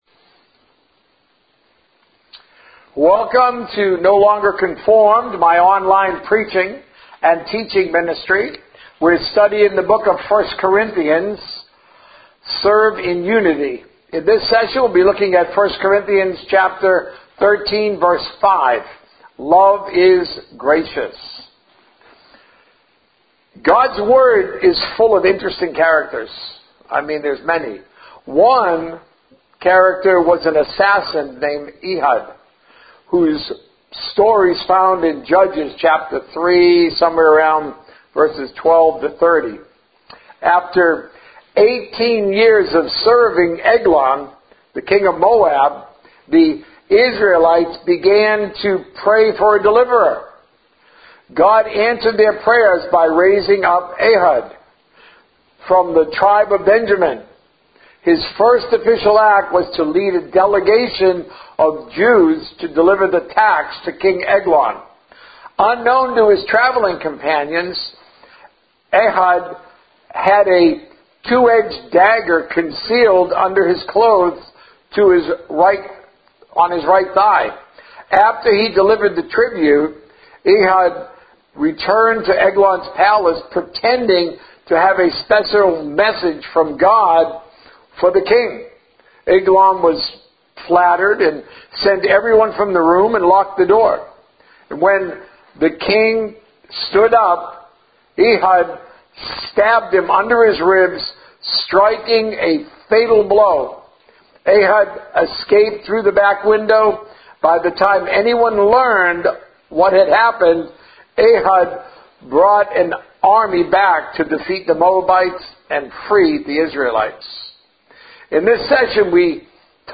A message from the series "Seven Roles of Christ."